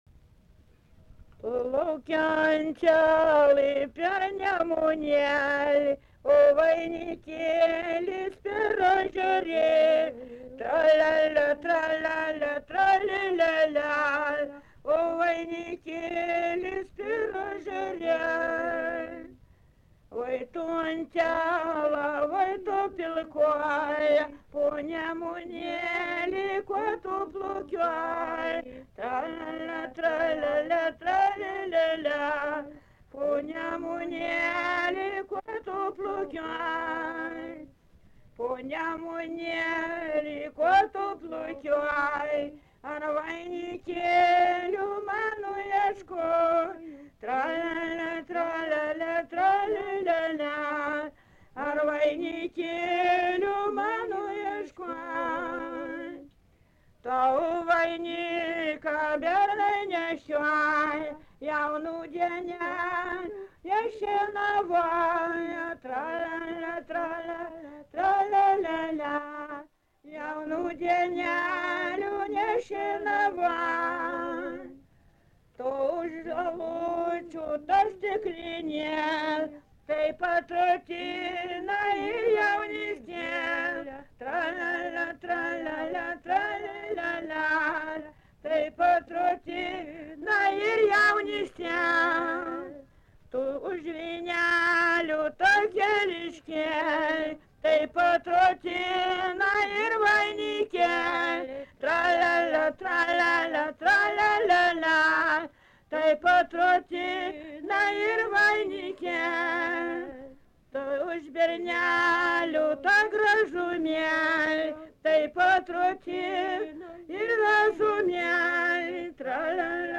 Erdvinė aprėptis Kvietkinė
Atlikimo pubūdis vokalinis
Dainuoja viena dainininkė